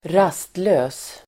Uttal: [²r'as:tlö:s]